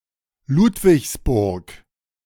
Ludwigsburg (German pronunciation: [ˈluːtvɪçsˌbʊʁk]
De-Ludwigsburg.ogg.mp3